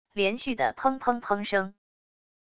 连续的砰砰砰声wav音频